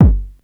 Kick_16.wav